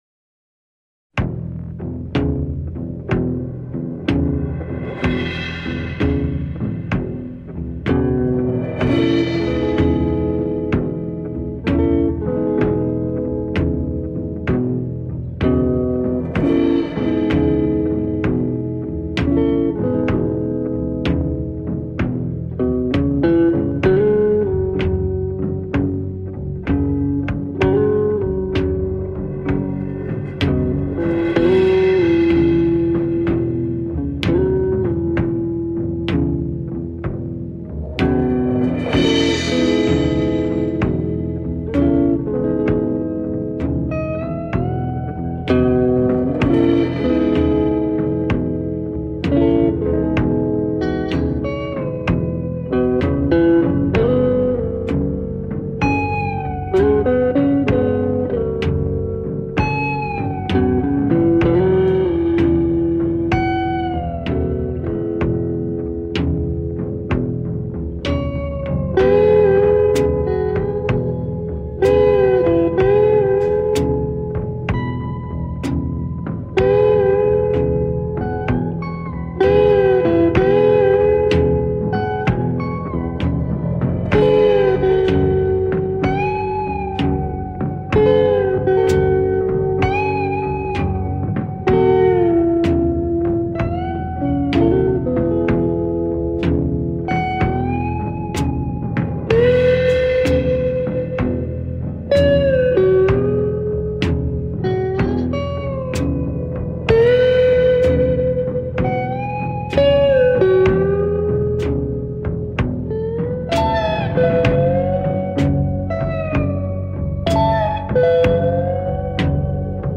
и такие непривычно тихие - никакого гитарного бум-бум.))